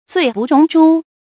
成語注音ㄗㄨㄟˋ ㄅㄨˋ ㄖㄨㄙˊ ㄓㄨ
成語拼音zuì bù róng zhū
發音讀音
罪不容誅發音